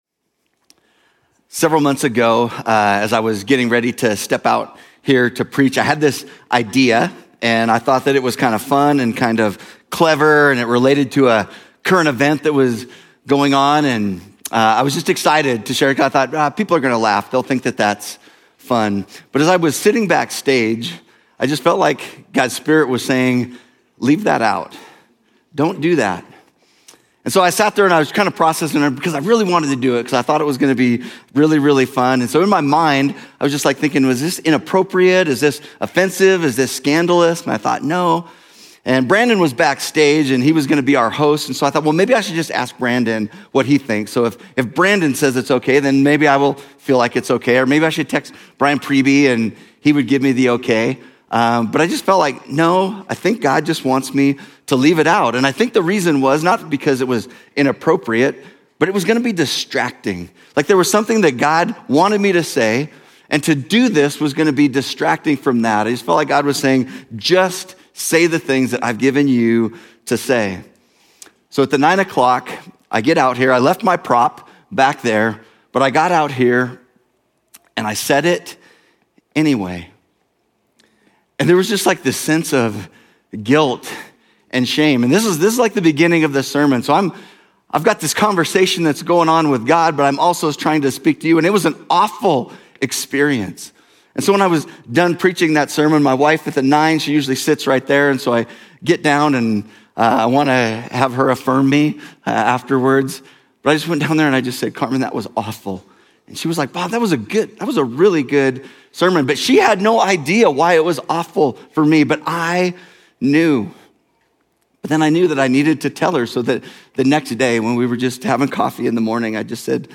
Journey Church Bozeman Sermons Book of Romans: Offer Yourselves to God Apr 06 2025 | 00:43:01 1x 00:00 / 00:43:01 Subscribe Share Apple Podcasts Overcast RSS Feed Share Link Embed